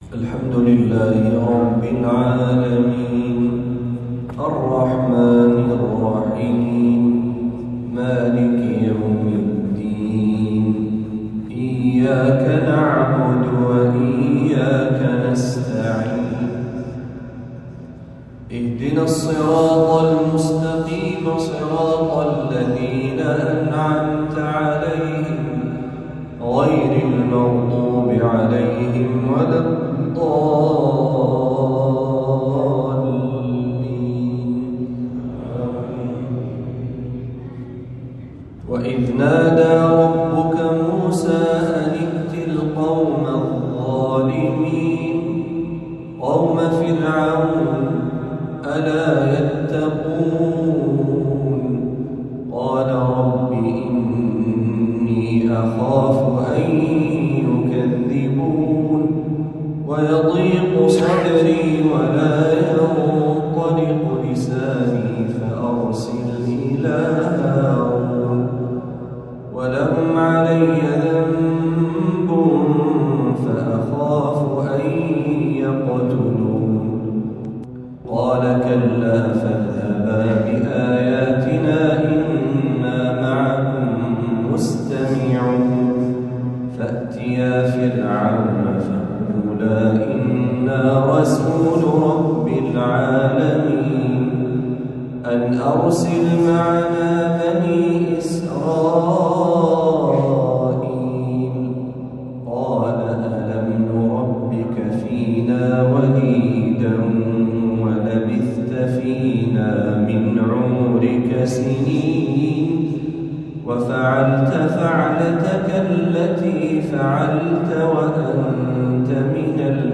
ما تيسر من سورة الشعراء | عشاء الاثنين ٦ ربيع الأول ١٤٤٦هـ > 1446هـ > تلاوات الشيخ محمد برهجي > المزيد - تلاوات الحرمين